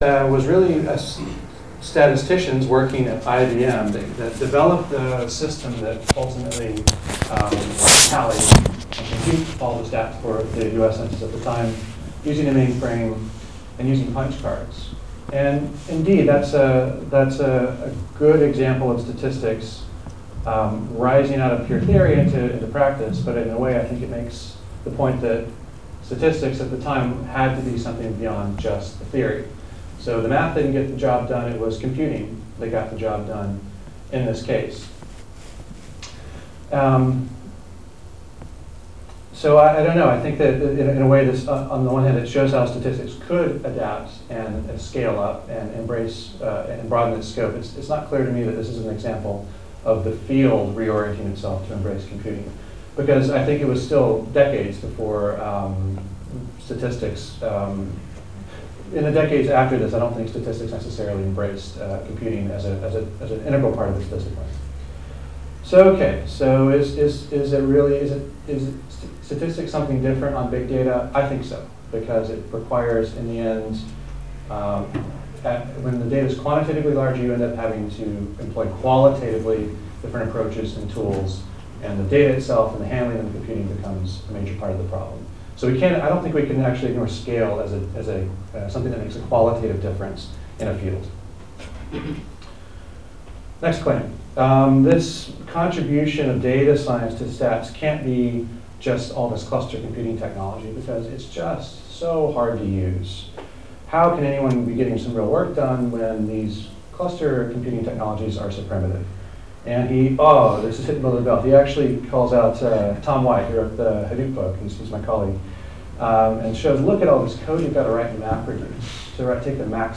(Recording is incomplete.)